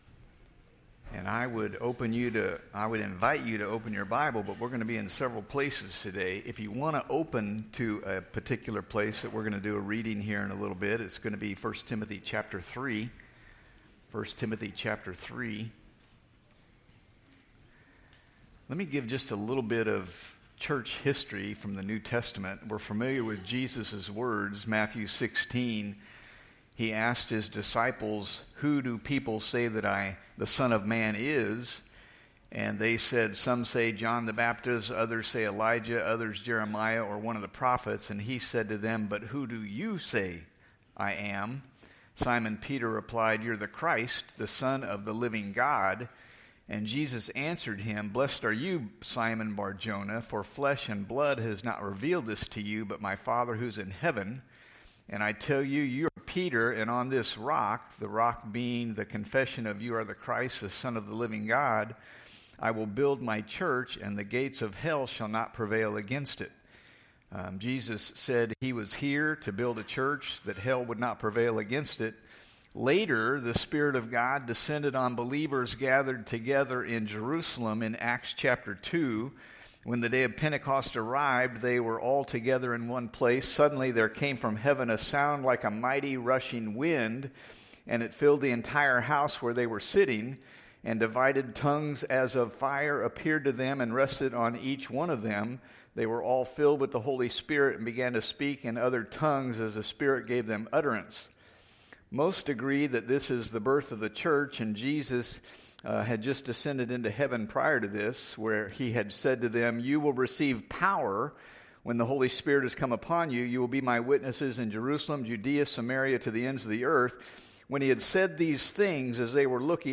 Sermons | Lone Jack Baptist Church